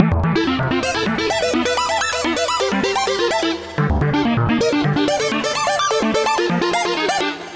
My Musicbox E 127.wav